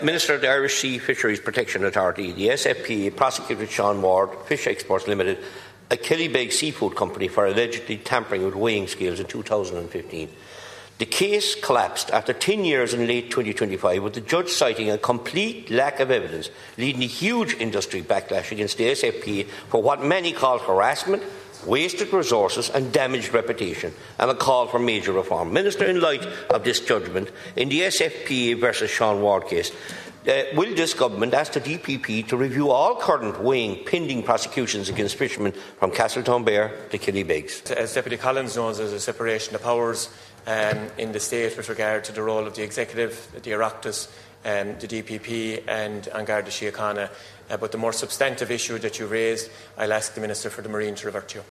Independent Ireland leader Michael Collins raised the issue in the Dáil.
He says the SFPA’s approach in the Donegal case raises serious questions: